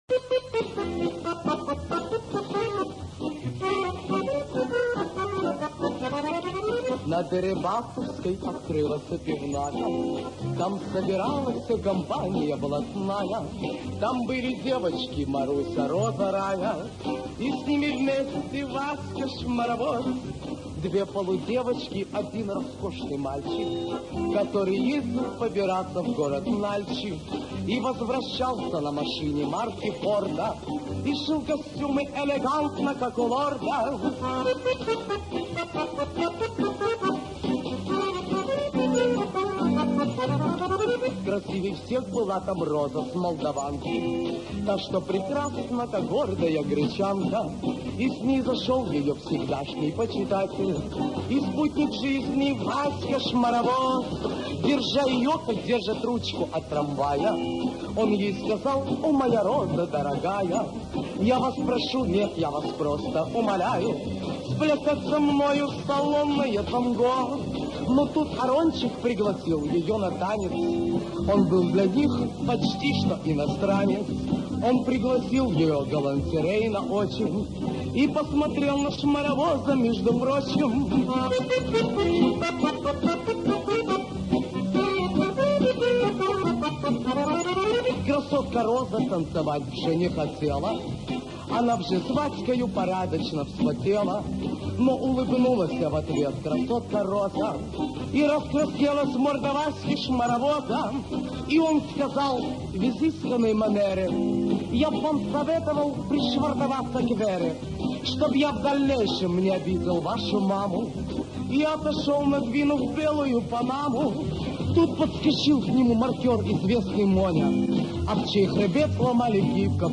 стилистически в одессом духе